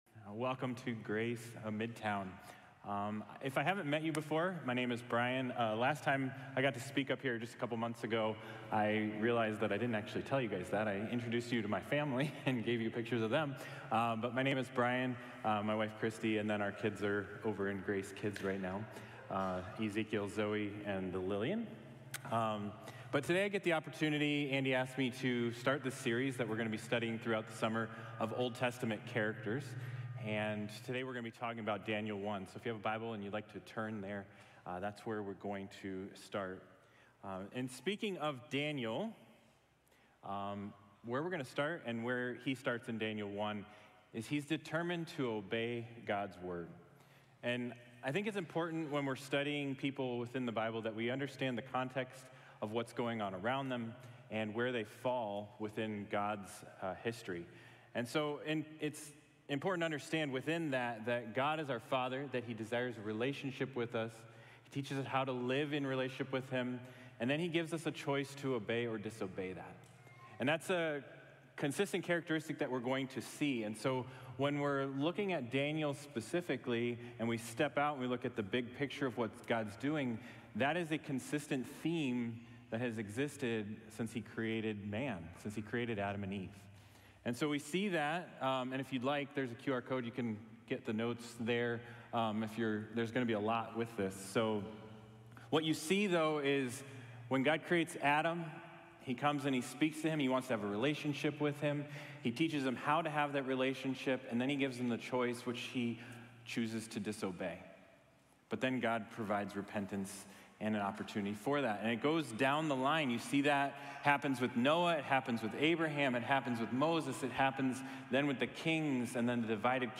Daniel: Determined to Obey God’s Word | Sermon | Grace Bible Church